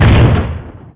mega_bouncehard3.wav